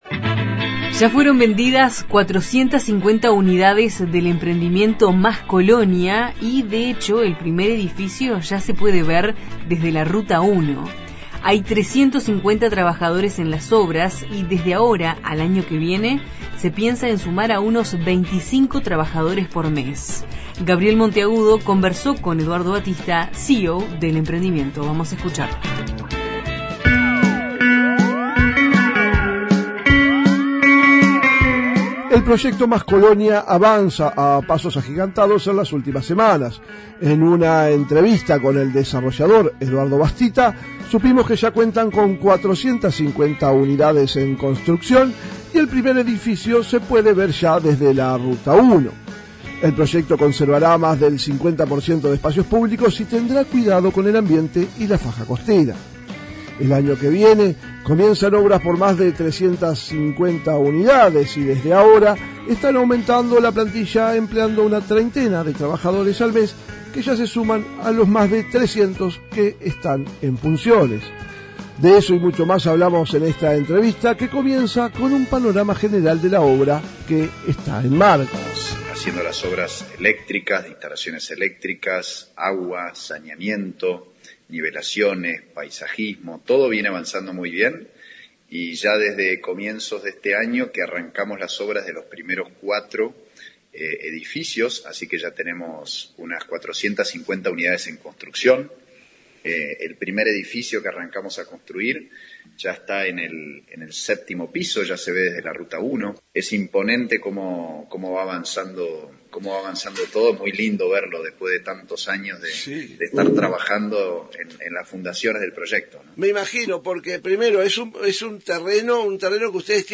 Los informes de nuestros corresponsales en Treinta y Tres, Colonia Oeste y Lavalleja.